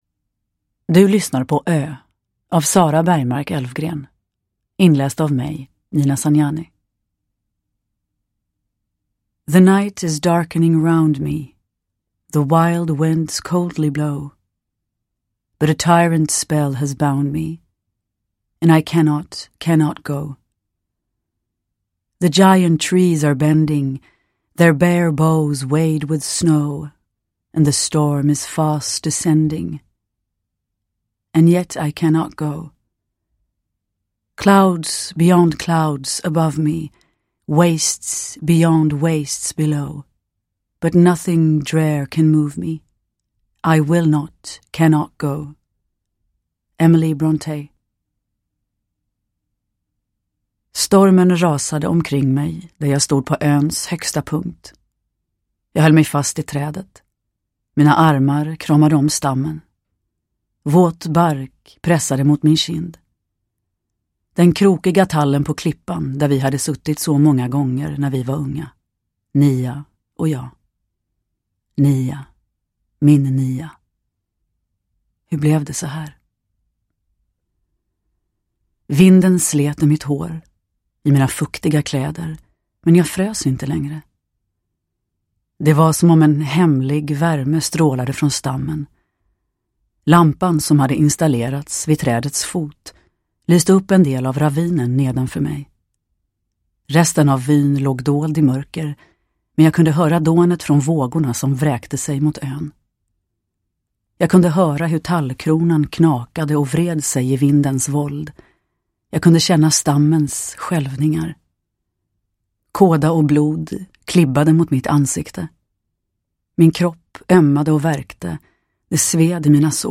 Ö – Ljudbok
Uppläsare: Nina Zanjani